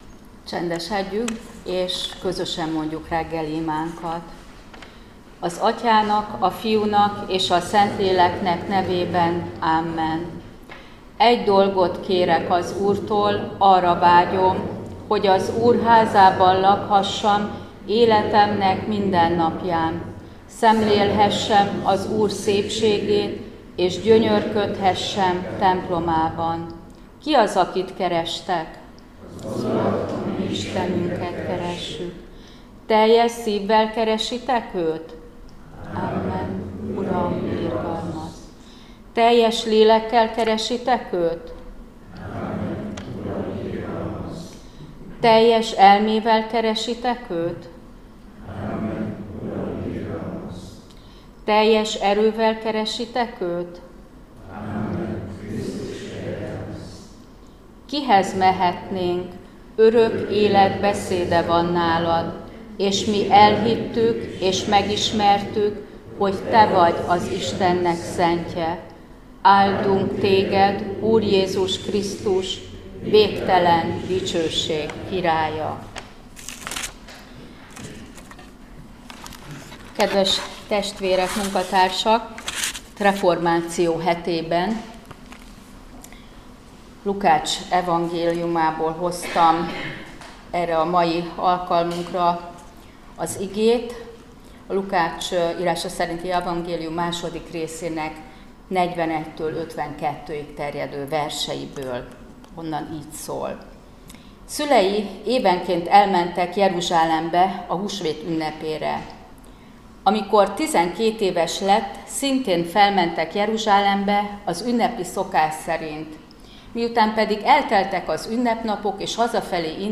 Áhítat, 2024. október 29.